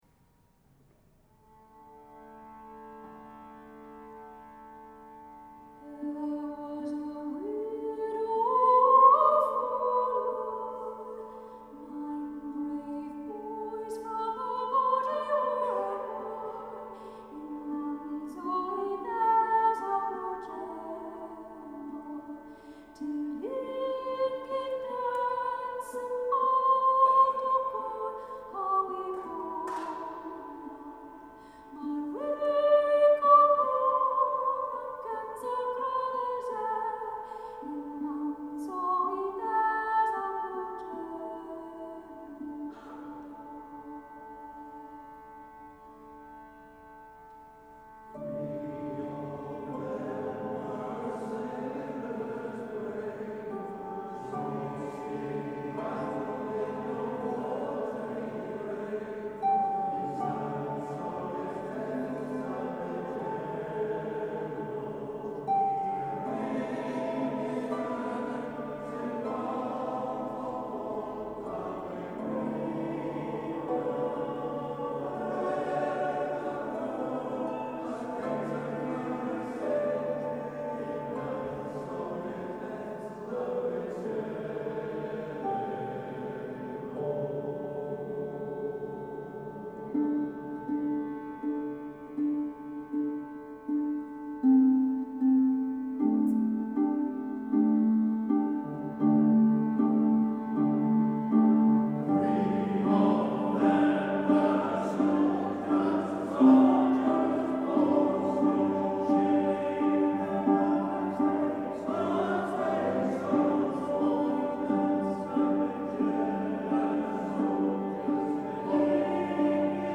Sung by Eimear Quinn and the local Loveny Male Voice Choir at Truro Cathedral on 17th March 2026.
Nine-Brave-Boys-with-Loveny-MVC-feat.-Eimear-Quinn-and-The-Breathe-Upon-The-Flame-Ensemble.mp3